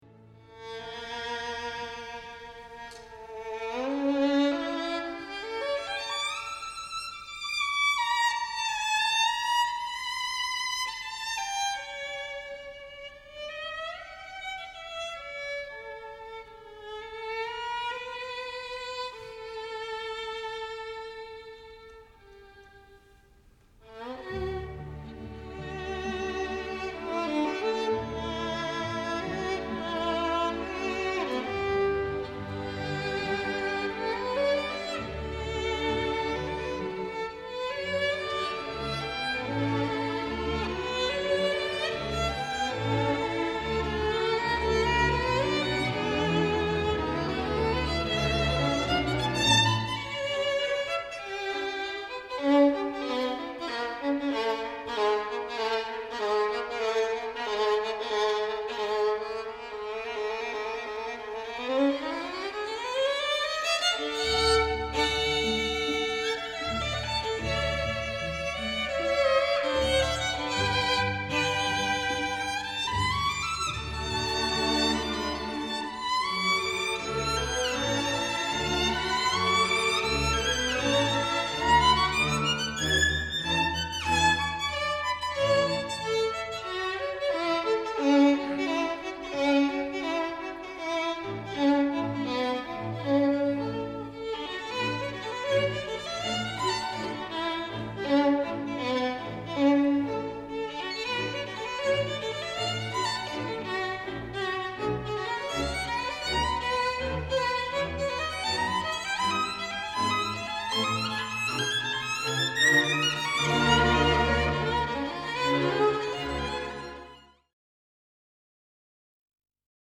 violinkonzert-op.35-tschaikowsky.mp3